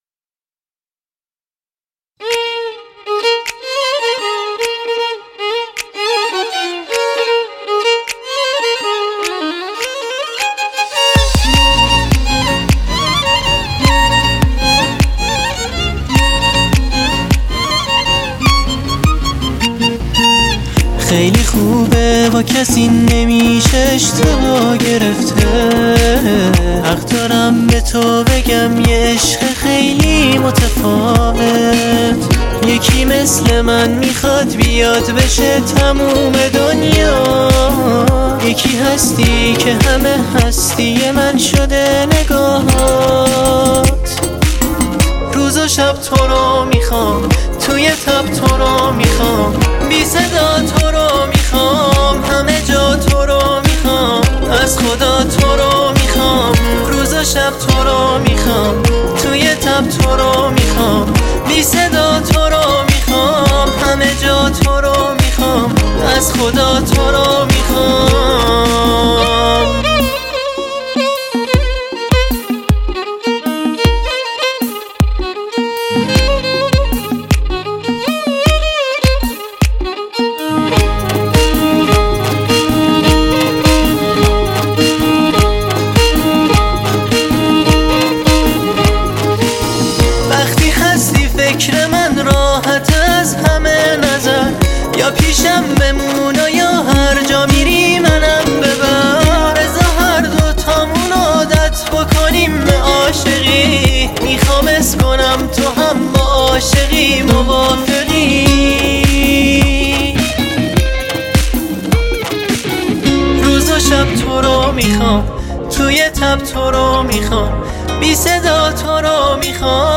دانلود آهنگ شاد , آهنگ شاد ایرانی